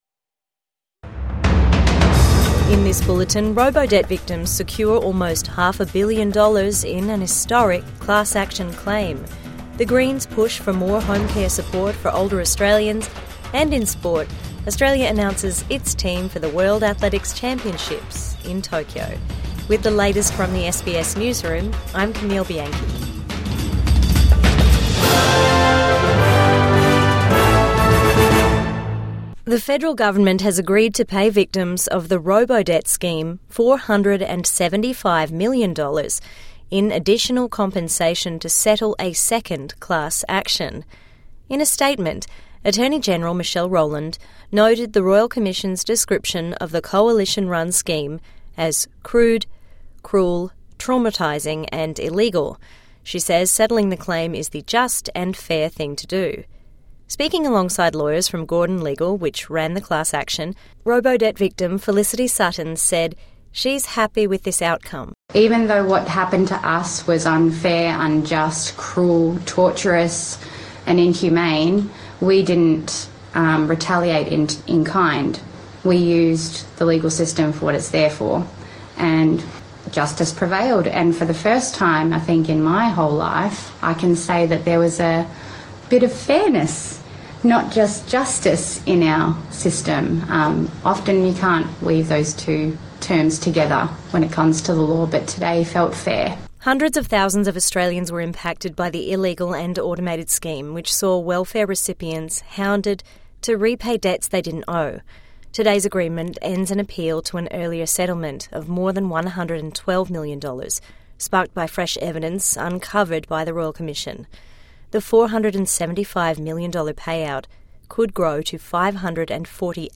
Robodebt victims awarded more compensation in landmark class action | Evening News Bulletin 4 September 2025